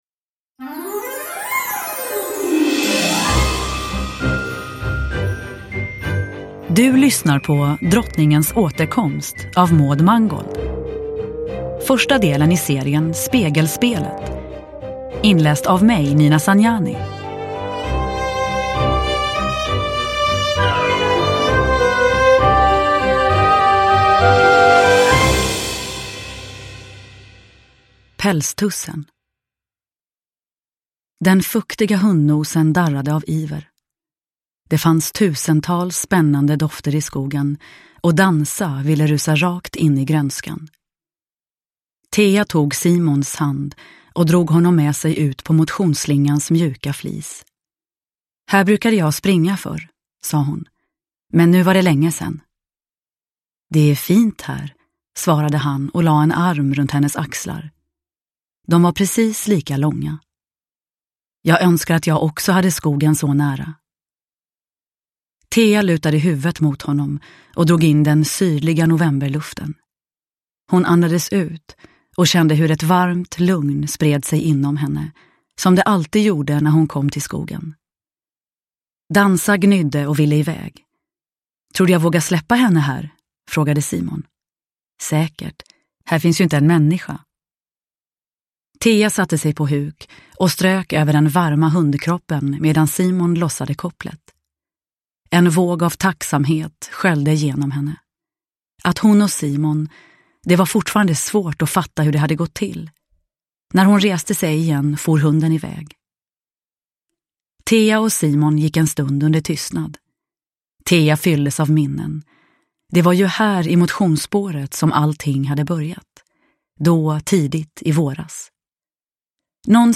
Drottningens återkomst – Ljudbok – Laddas ner
Uppläsare: Nina Zanjani